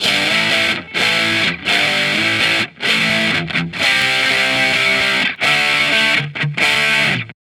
Guitar Licks 130BPM (11).wav